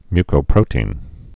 (mykō-prōtēn, -prōtē-ĭn)